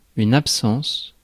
Prononciation
Synonymes départ fugue Prononciation France: IPA: [ap.sɑ̃s] Accent inconnu: IPA: /ab.sɑ̃s/ Le mot recherché trouvé avec ces langues de source: français Traduction 1.